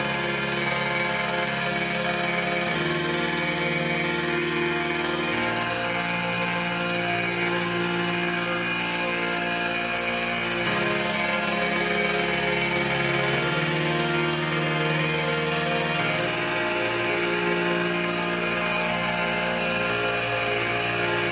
描述：伴随着Native Instruments Reaktor和Adobe Audition创作的两段式环境循环；大量的运动：旋转的感觉和大量的平移
标签： 环境 运动 2-条 平移 旋涡状 声音设计
声道立体声